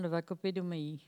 Maraîchin
Catégorie Locution